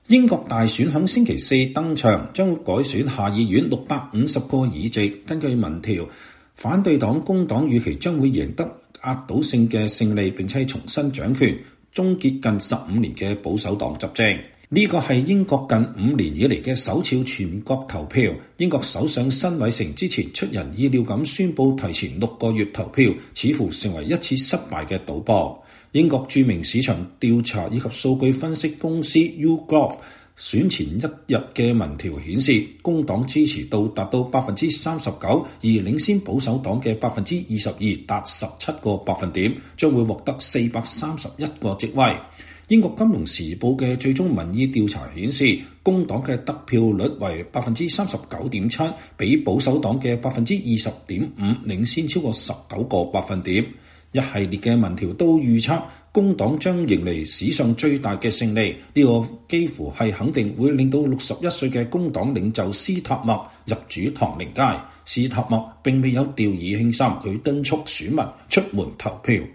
英國舉行大選，人們在倫敦的一個投票站排隊投票。